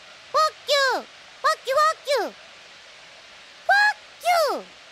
Play, download and share Fah kyu! original sound button!!!!
meow_g49Gx3O.mp3